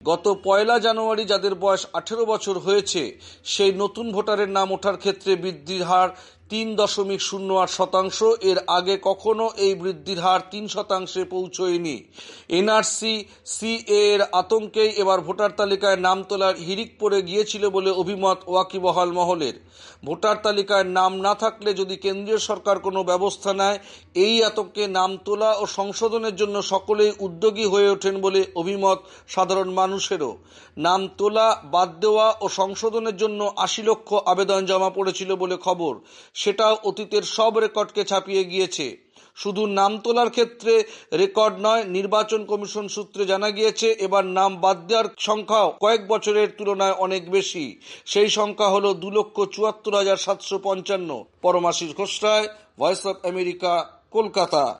কলকাতা থেকে